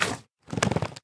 Minecraft Version Minecraft Version 25w18a Latest Release | Latest Snapshot 25w18a / assets / minecraft / sounds / mob / magmacube / jump3.ogg Compare With Compare With Latest Release | Latest Snapshot